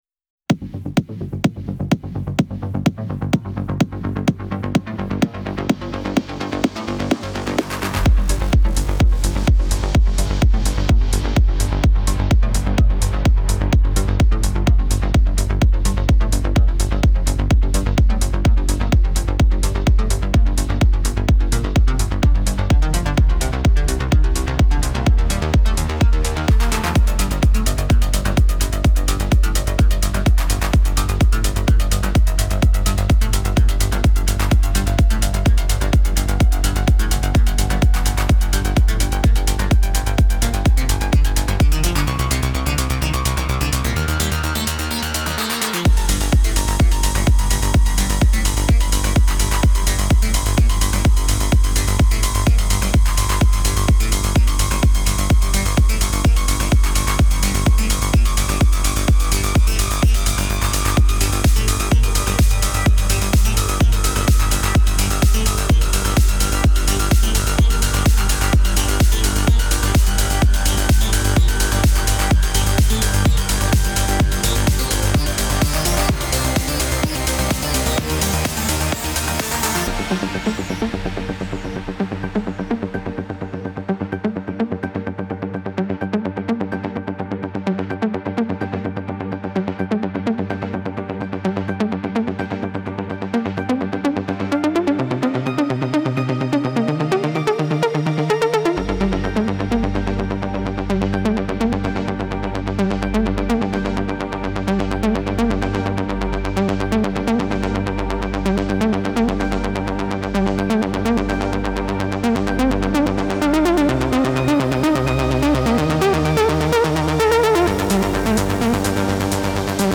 پر‌انرژی
ترنس